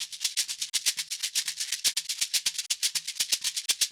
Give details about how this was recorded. Also below in a faux Ableton-style interface are all the original loops used to create the tracks.